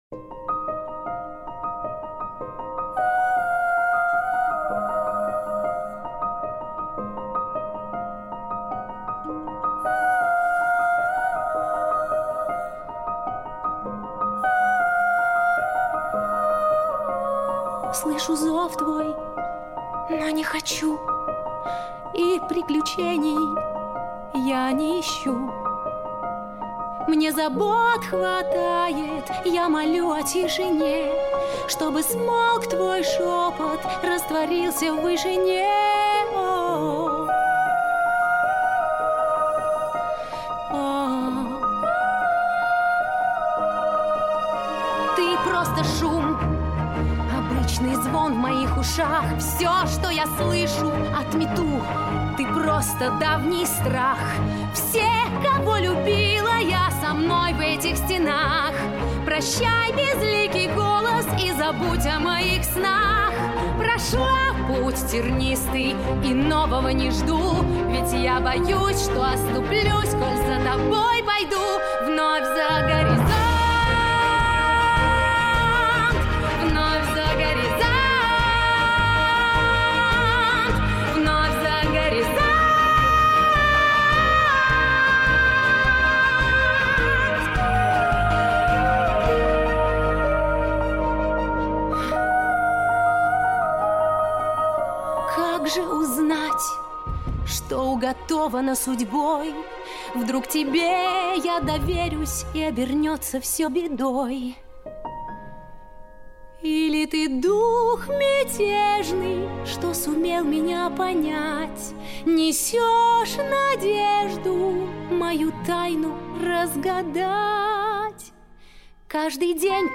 • Качество: Хорошее
• Жанр: Детские песни
🎶 Детские песни / Песни из мультфильмов